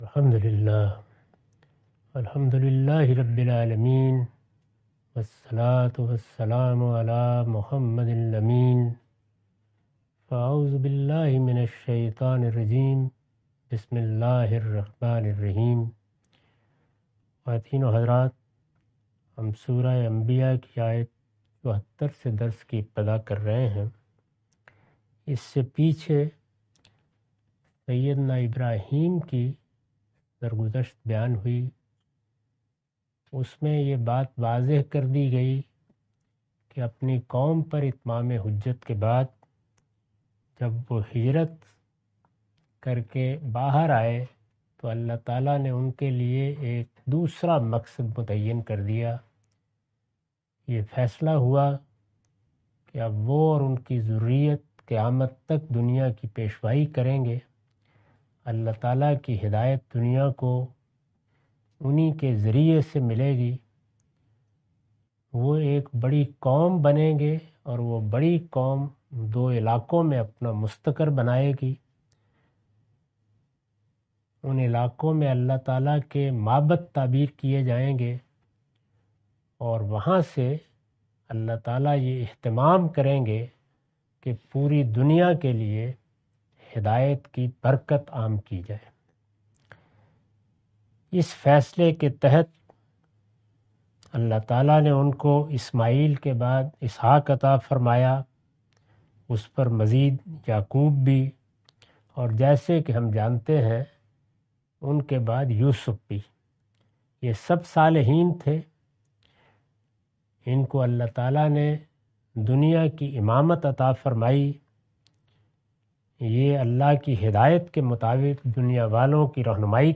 Surah Al-Anbiya A lecture of Tafseer-ul-Quran – Al-Bayan by Javed Ahmad Ghamidi. Commentary and explanation of verses 74-75.